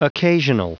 Prononciation du mot occasional en anglais (fichier audio)
Prononciation du mot : occasional